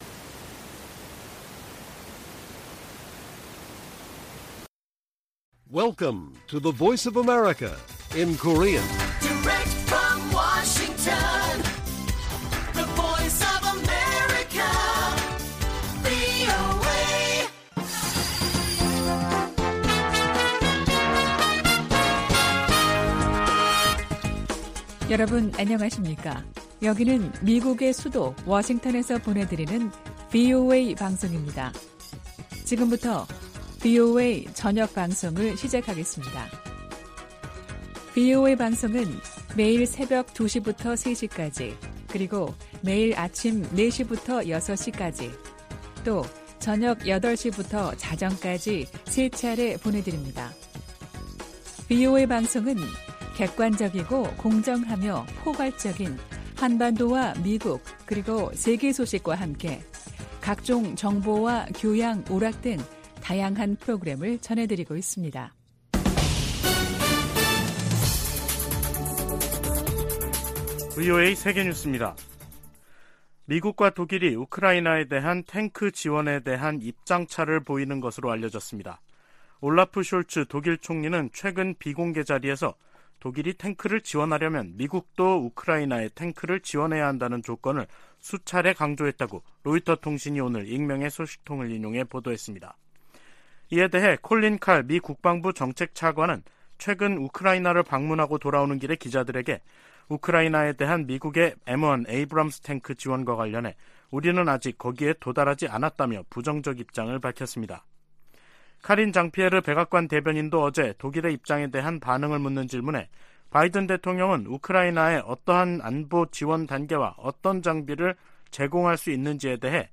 VOA 한국어 간판 뉴스 프로그램 '뉴스 투데이', 2023년 1월 19일 1부 방송입니다. 북한이 핵보유국을 자처해도 미국의 한반도 비핵화 목표에는 변함이 없다고 국무부가 밝혔습니다. 김정은 국무위원장이 불참한 가운데 열린 북한 최고인민회의는 경제난 타개를 위한 대책은 보이지 않고 사상 통제를 강화하는 조치들을 두드러졌다는 분석이 나오고 있습니다.